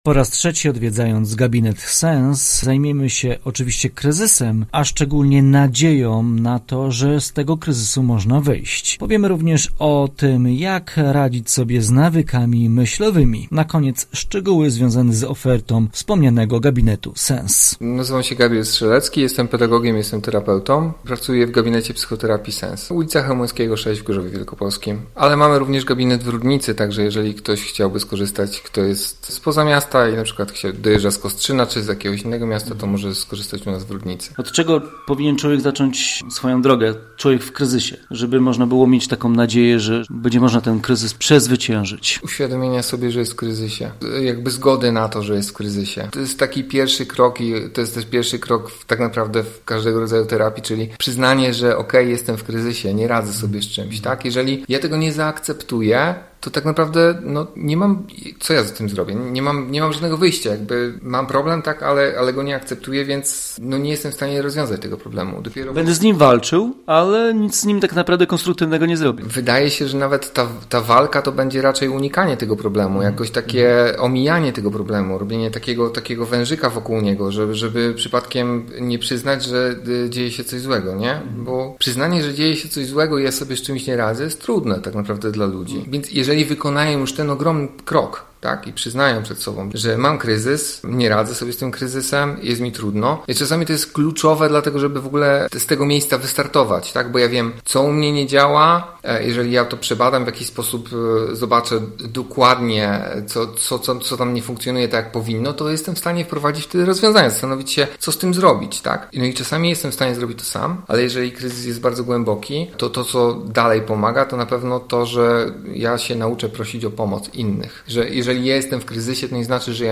Cykl rozmów o uzależnieniach. Jak nadzieja może wpłynąć na wyjście z kryzysu i co robią z nami nawyki myślowe?